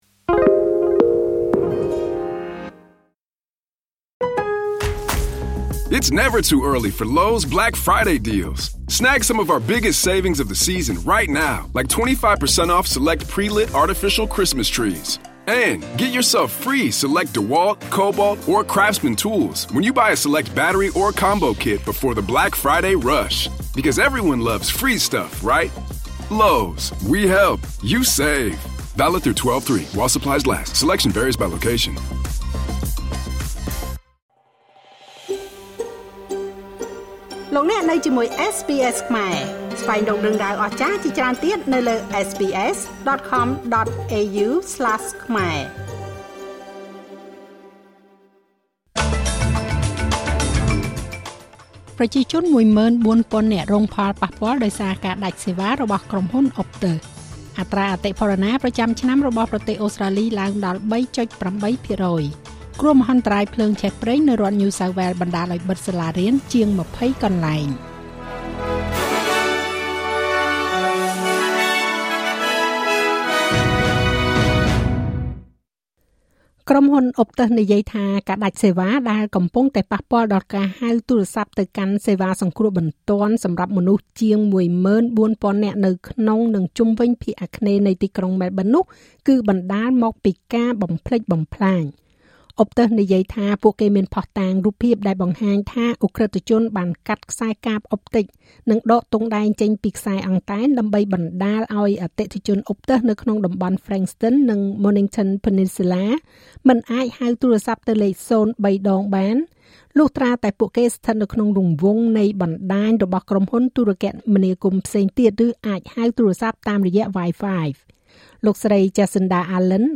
នាទីព័ត៌មានរបស់SBSខ្មែរ សម្រាប់ថ្ងៃពុធ ទី២៦ ខែវិច្ឆិកា ឆ្នាំ២០២៥